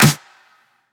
pcp_clap05.wav